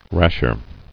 [rash·er]